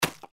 Index of /server/sound/npc/gecko/foot
fs_gecko_r04.mp3